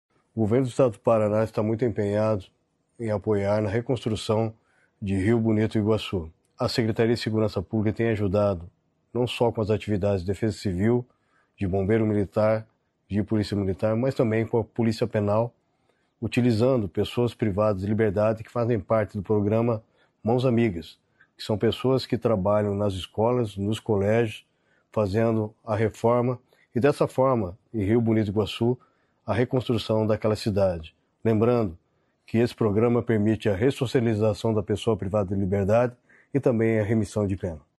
Sonora do secretário da Segurança Pública, Hudson Leôncio Teixeira, sobre o apoio da pasta nas ações em Rio Bonito do Iguaçu